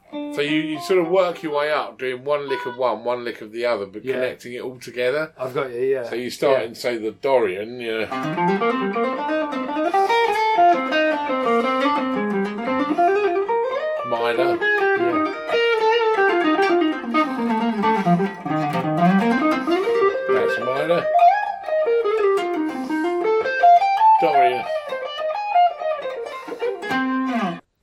lesson-27-country-rock-pop-demo-5-dorian-to-aeolian.mp3